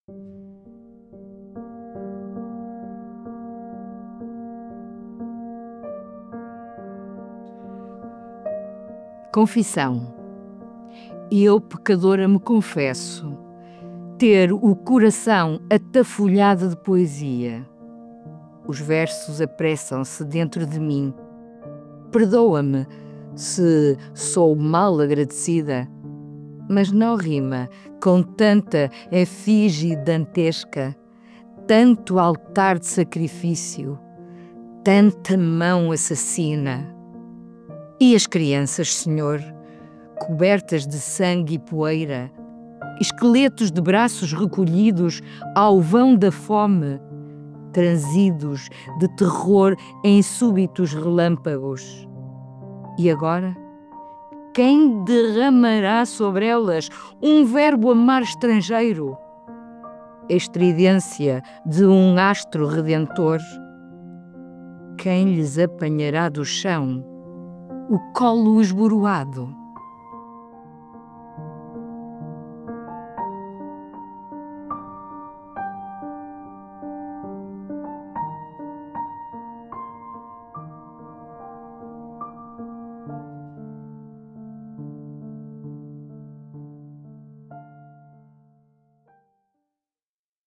Música: Soft Calm Piano Music